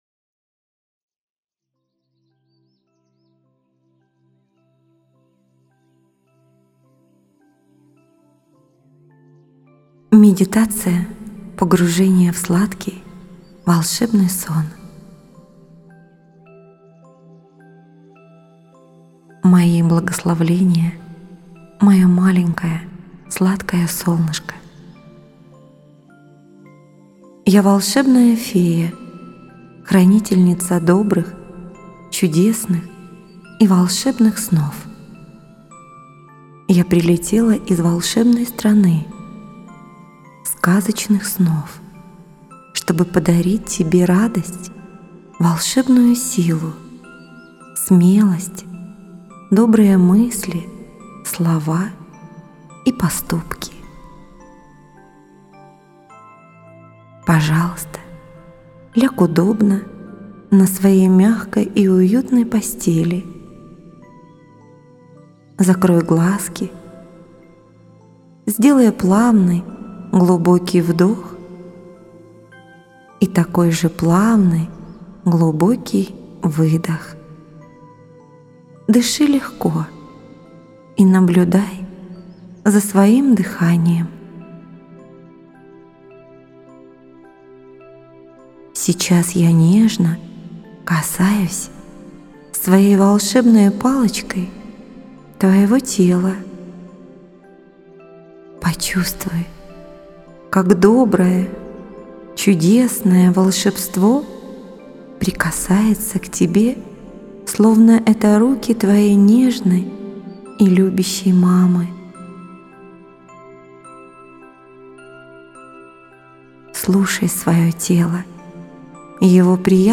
Медитация Путешествие в страну сказочных чудес
Медитация_Путешествие_в_страну_сказочных_чудес_1_1.mp3